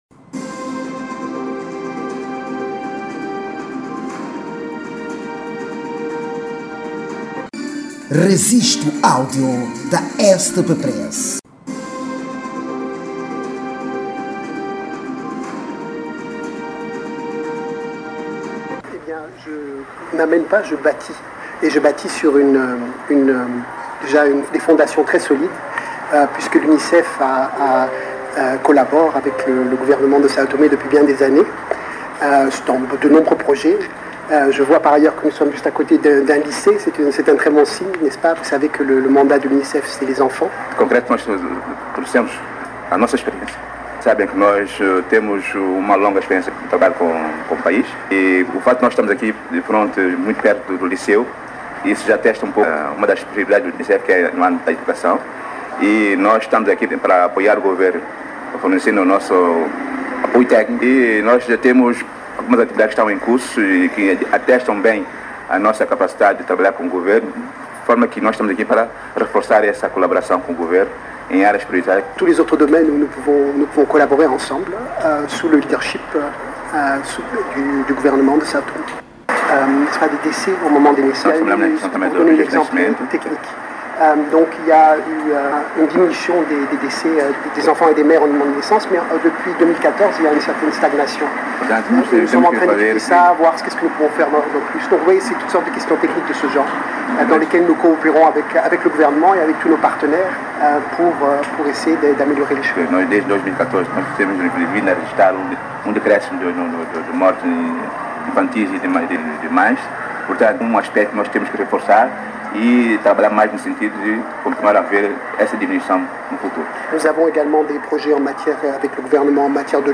A cerimónia de acreditação ocorreu no ministério dos Negócios Estrangeiros, cooperação e Comunidades, durante o qual, o alemão Stephan Grieb, apresentou a Botelho as cartas que acreditam este diplomata como novo representante dessa Agência da ONU.